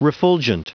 Prononciation du mot : refulgent